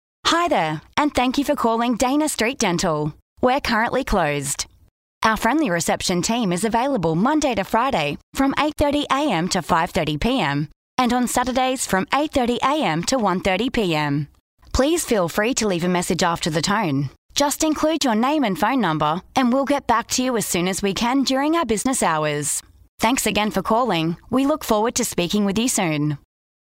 On hold messages, prompts and voicemail greetings can help increase sales within your budget.
0930FV2_Phone_Example.mp3